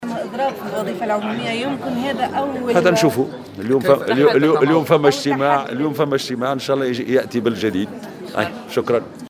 وقال في تصريح لمراسل "الجوهرة أف أم"، هناك اجتماع ثان اليوم وسنرى ما قد يتوصل إليه، مردفا بالقول "نأمل في أن ياتي بجديد".